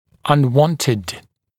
[ʌn’wɔntɪd][ан’уонтид]нежелательный